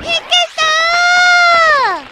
Worms speechbanks
Excellent.wav